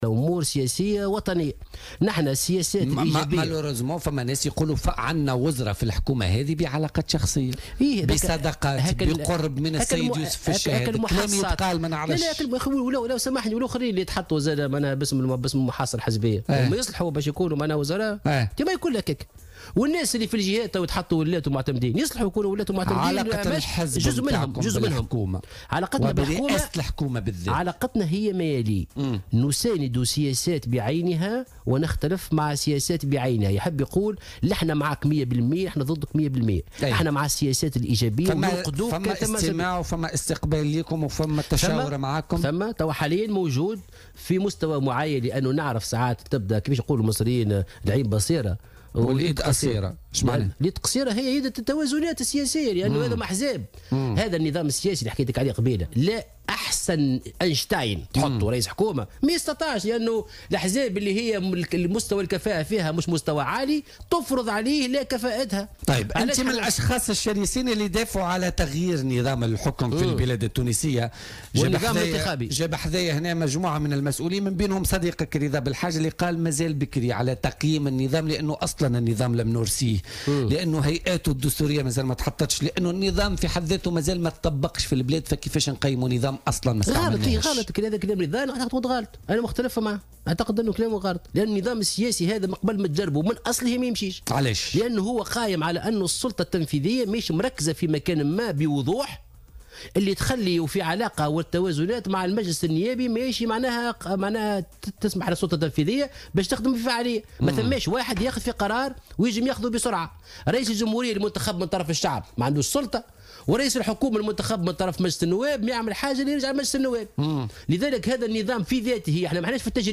أكد الأمين العام لحركة مشروع تونس محسن مرزوق ضيف بولتيكا اليوم الإثنين 6 نوفمبر 2017 أنه مع تغيير النظام السياسي والانتخابي في تونس لأن النظام الحالي لا يعمل بل يعطل المسار لأنه قائم على أن السلطة التنفيذية ليست مركزة في مكان واحد بوضوح حسب قوله.